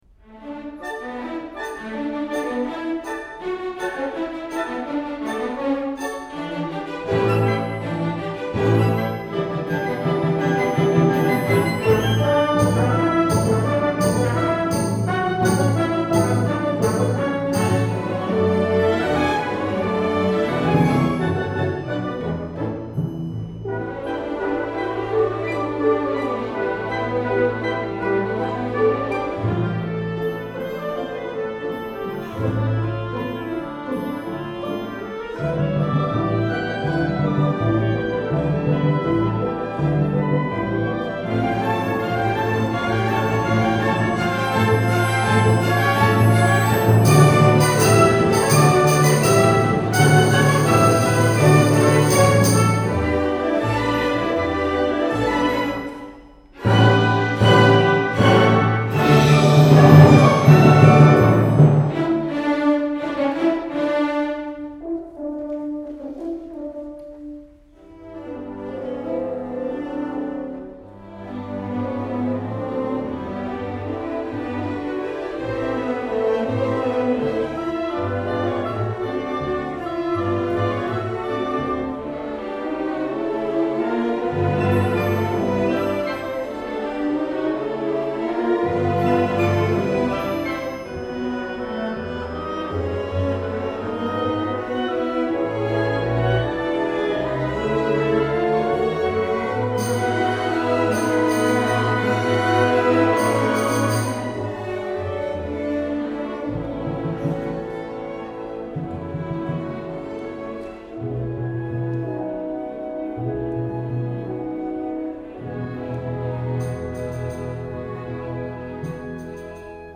Symphony Orchestra
Students of all majors and community members are eligible to participate.